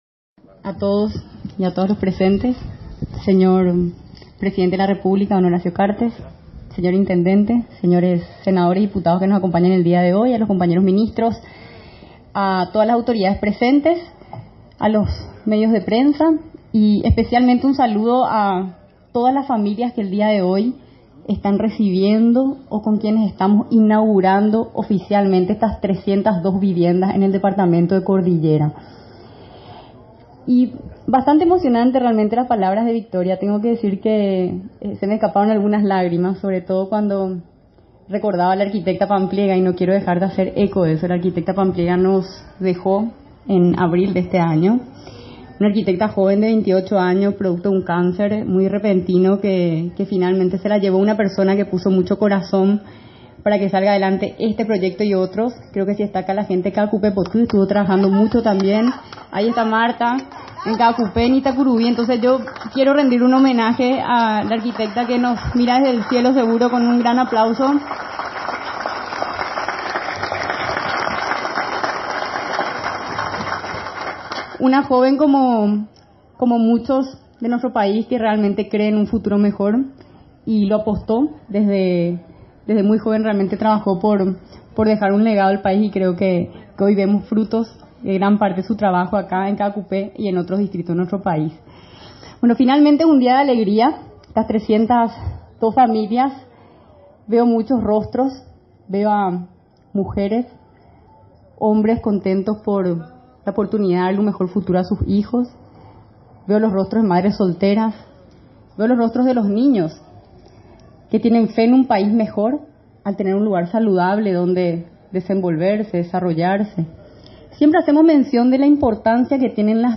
“Para el Gobierno Nacional cada vivienda representa el espacio geográfico donde cada familia construye los valores, los principios que finalmente son los cimientos de nuestra sociedad”, señaló la ministra de la Senavitat, Soledad Núñez, durante el acto de entrega de viviendas sociales y aportes, en Itacurubí de la Cordillera.